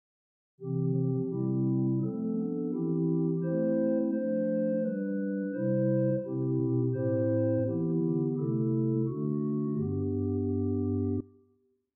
Wedgwood describes the tone of the Lieblichgedeckt as quieter, brighter and less thick than the Gedeckt or Stopped Diapason.
Lieblich Gedackt 8', Swell Prudhoe Methodist Church, Northumberland, Scotland arpeggio